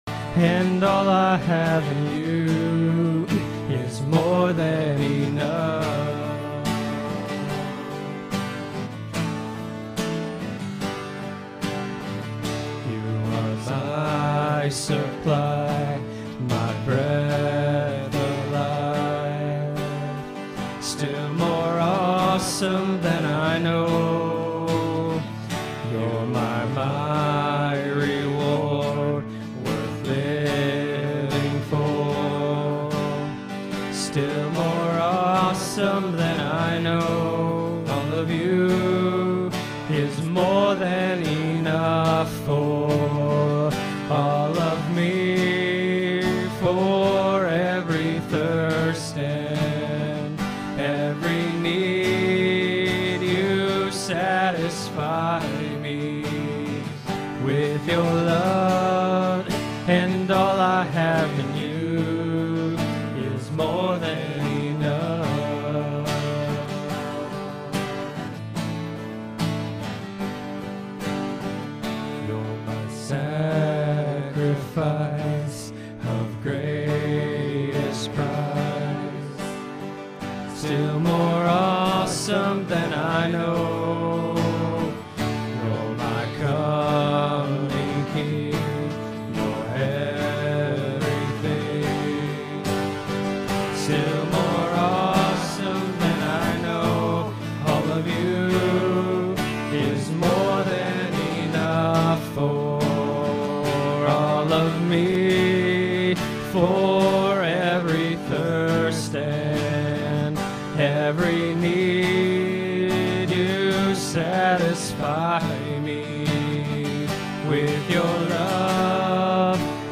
June 13, 2021 (Morning Worship)
Livestream-6-13-21.mp3